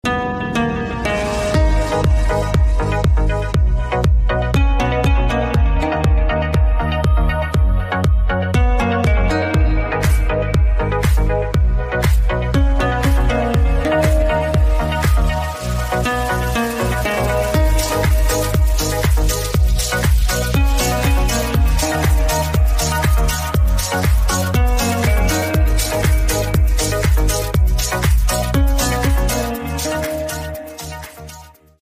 Рингтоны Без Слов
Танцевальные Рингтоны